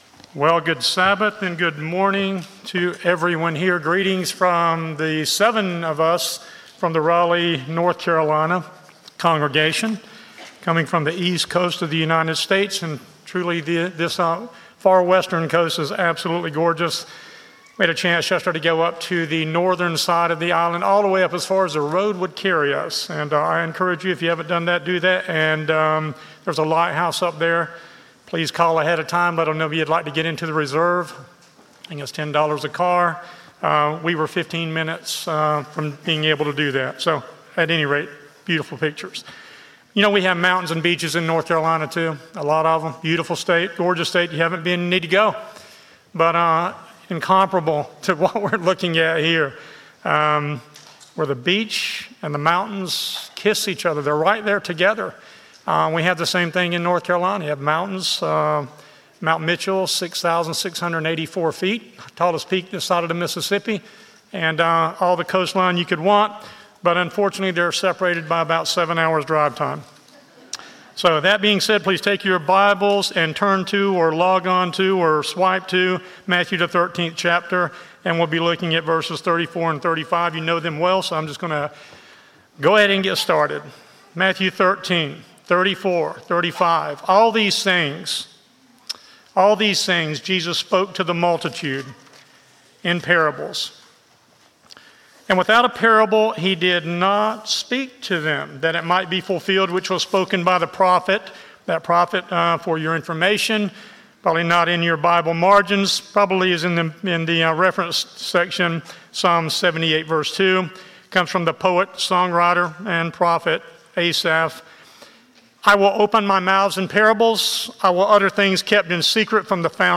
Given in Lihue, Hawaii